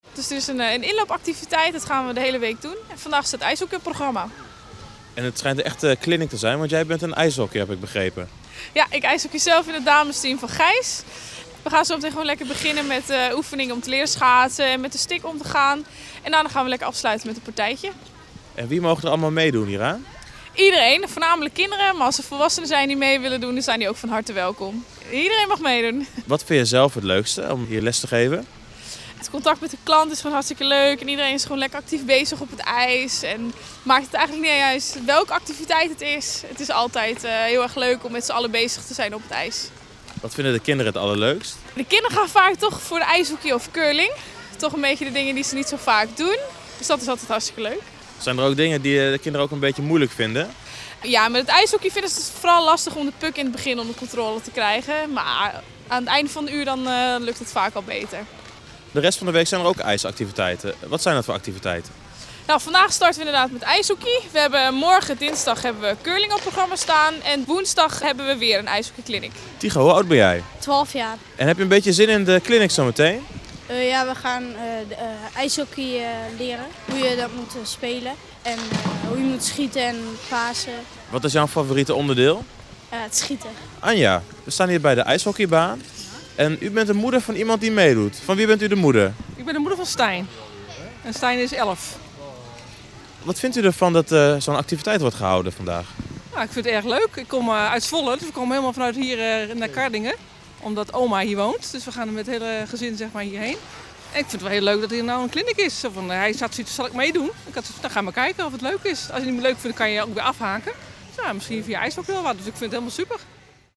was aanwezig bij de ijshockey-clinic.